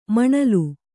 ♪ maṇalu